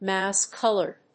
アクセントmóuse‐còlored